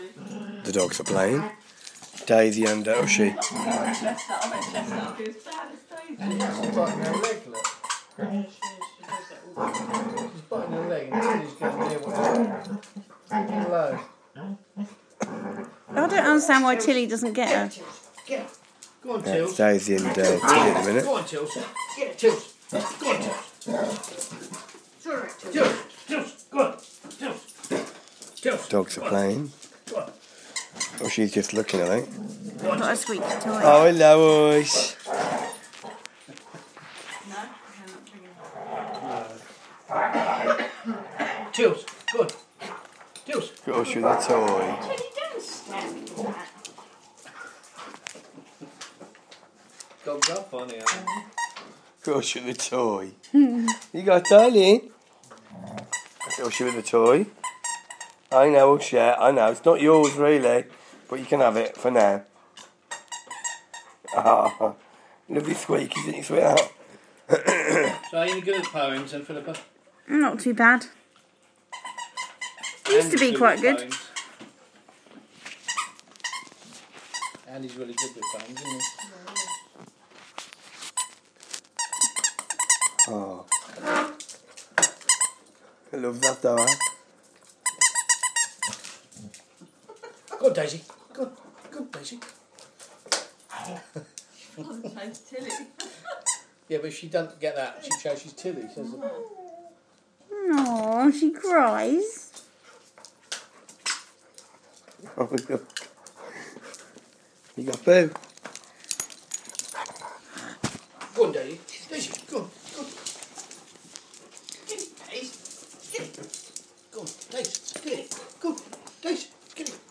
Dogs playing